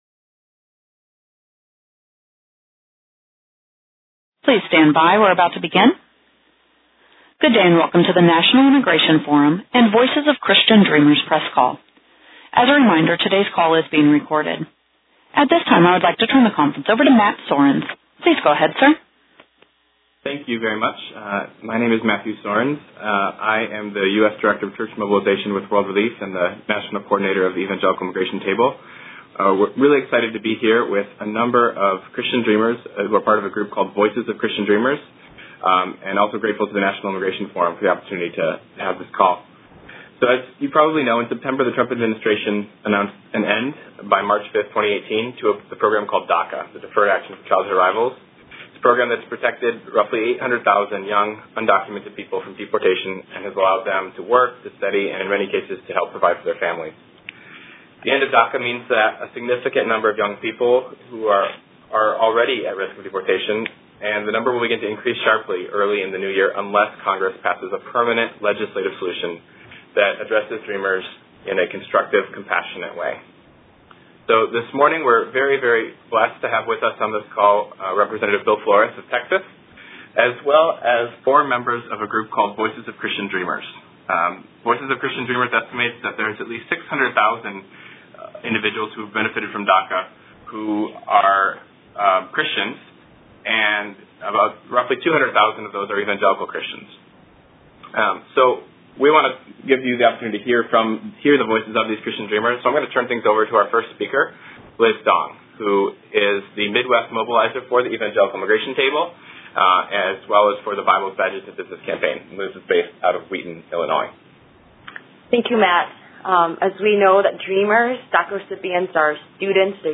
Click here for a recording of today’s call.